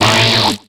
Cri de Ninjask dans Pokémon X et Y.